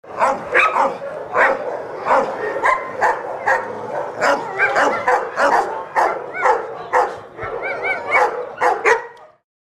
Dogs Barking Sm-sound-HIingtone
dogs-barking-sm.mp3